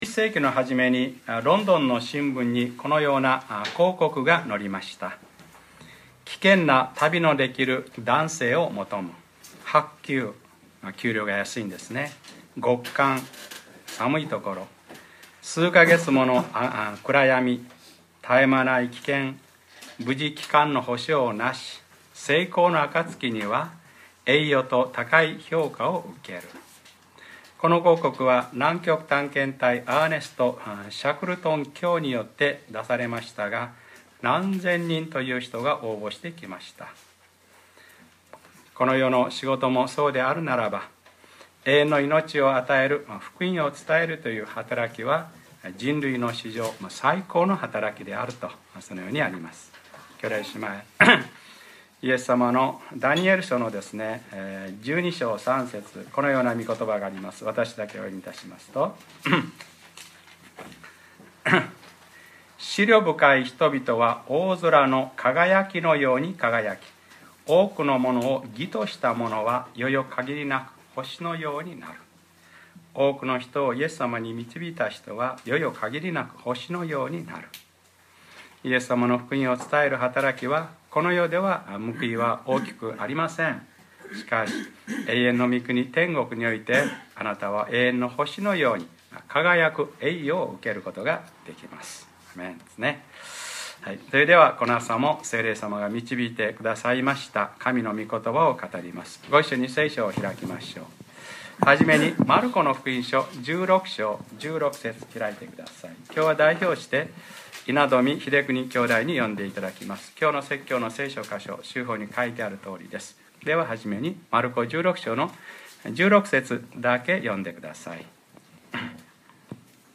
2013年11月10日(日）礼拝説教 『その水を私に下さい』